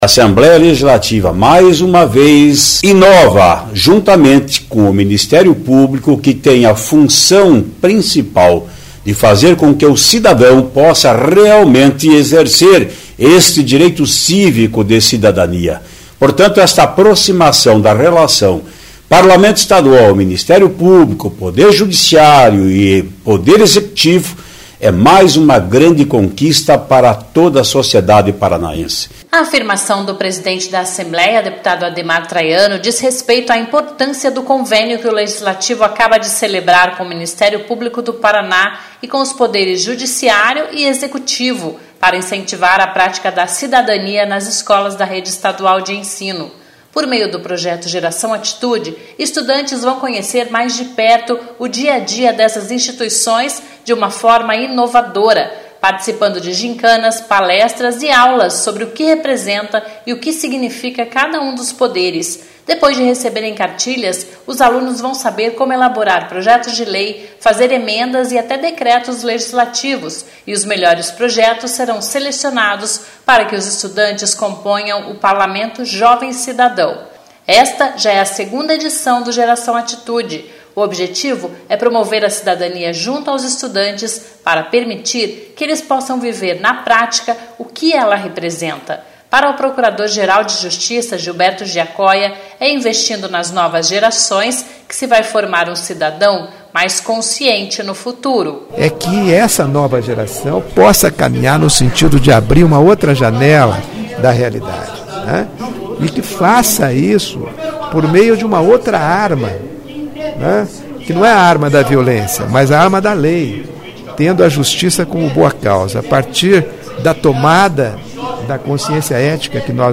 (começa com Traiano falando sobre o projeto))